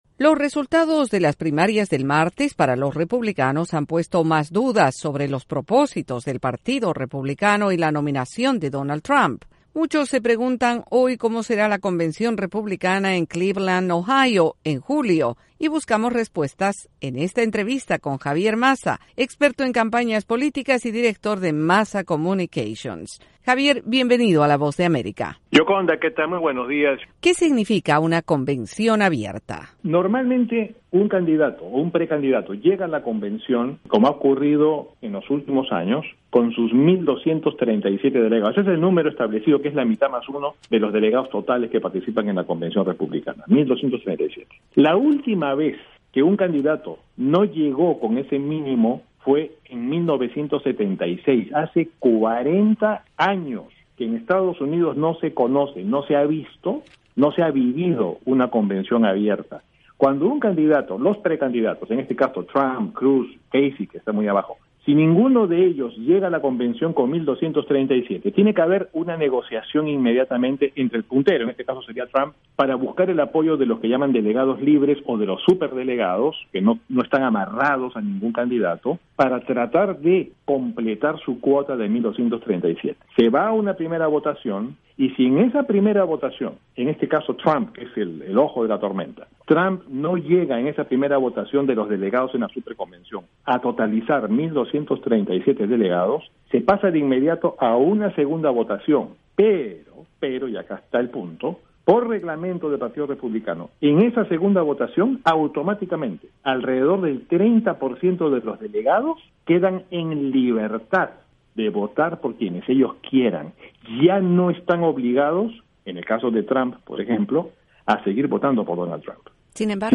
Entrevista con el analista político